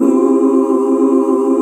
HUH SET C.wav